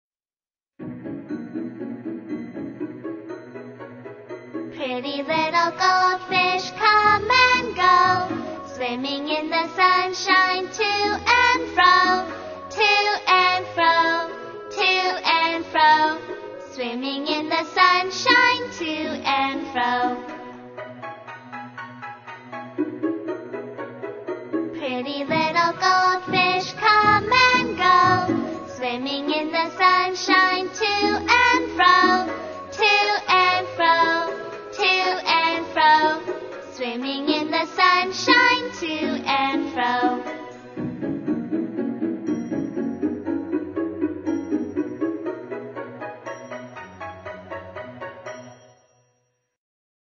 音乐节奏活泼动人的英文儿歌